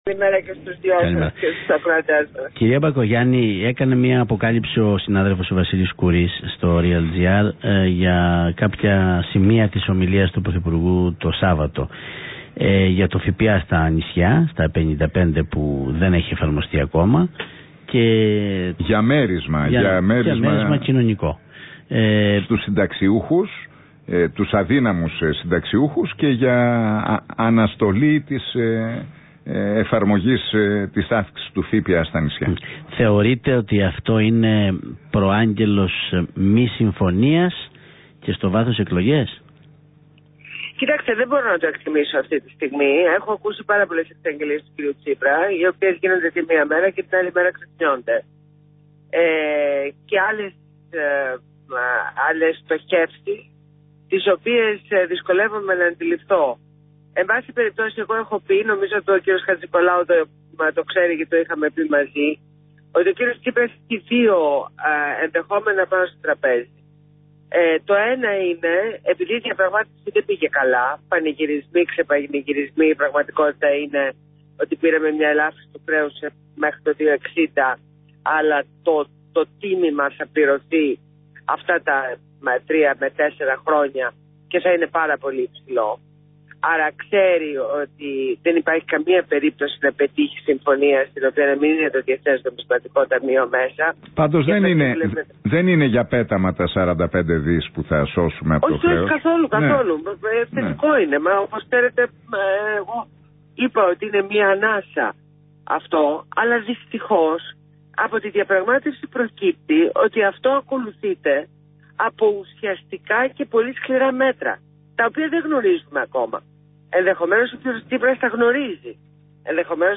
Συνέντευξη στο ραδιόφωνο του REALfm